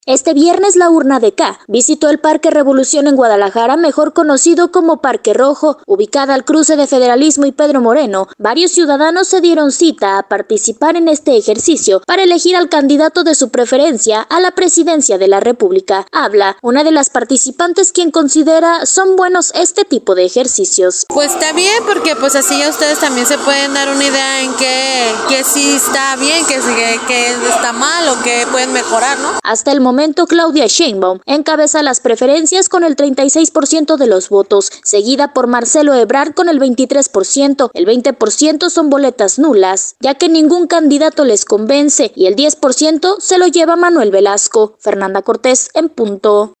Este viernes la Urna DK visitó el Parque Revolución, en Guadalajara ubicado al cruce de Federalismo y Pedro Moreno, varios ciudadanos participaron en este ejercicio para elegir al candidato de su preferencia a la presidencia de la República, comentó una de las participantes quien considera son buenos este tipo de ejercicios.